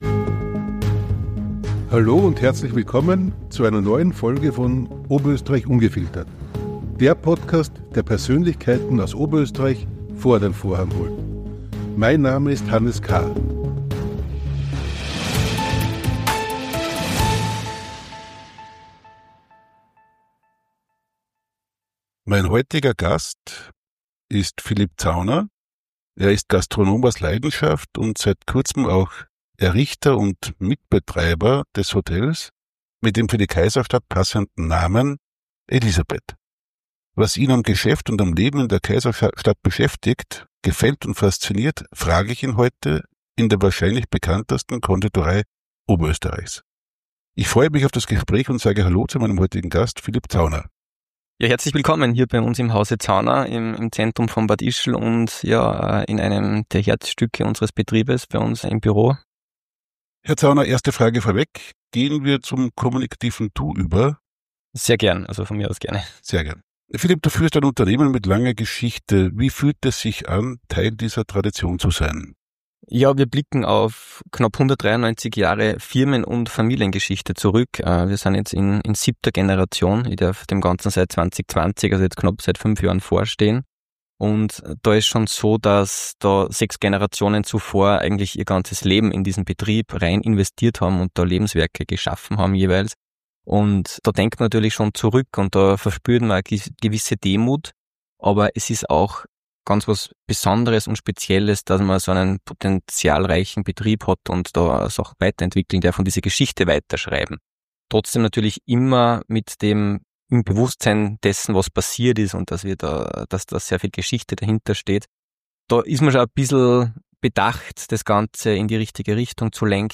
Freuen Sie sich auf ein inspirierendes Gespräch über Mut, Demut, Verantwortung – aber auch Genuss und Lebensfreude im Salzkammergut.